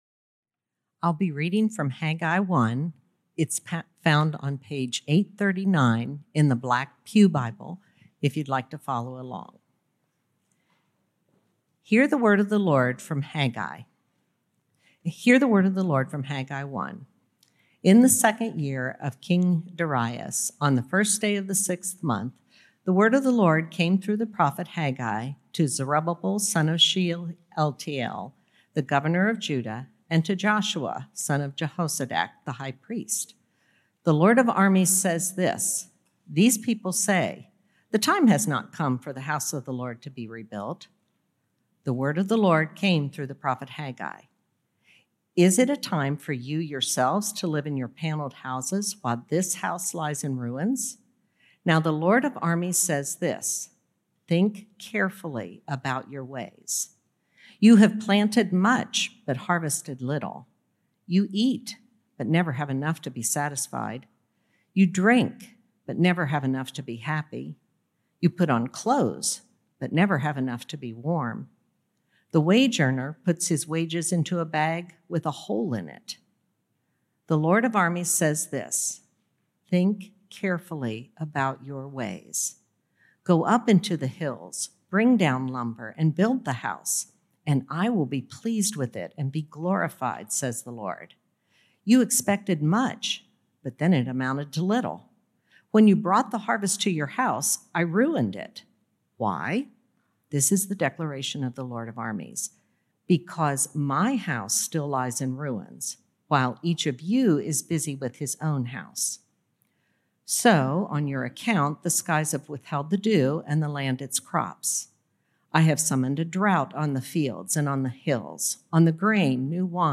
June 13th Sermon